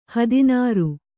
♪ hadināru